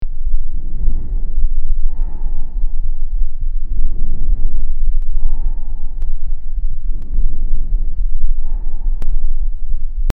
Breath sounds
Bronchial/Tracheal High Pitched, tubular  1:2 Over the trachea
• Bronchial/Tracheal:
01-bronchial-breath-sounds.mp3